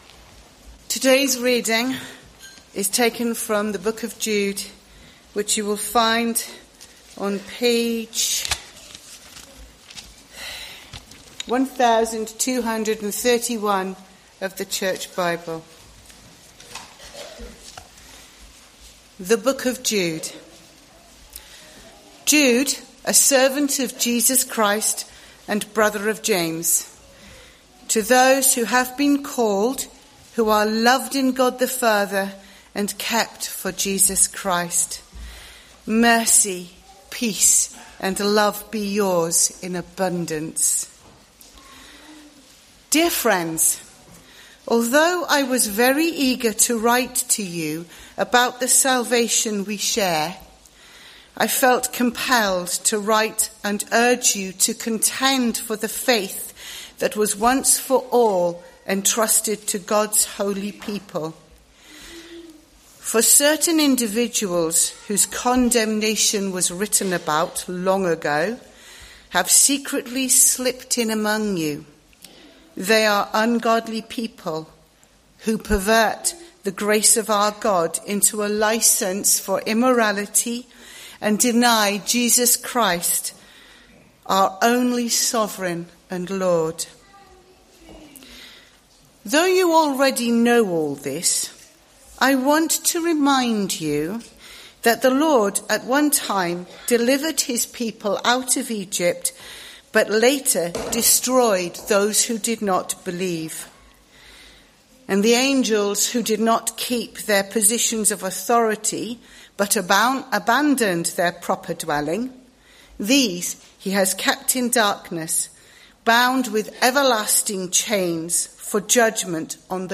Morning Service
All-Age Service Sermon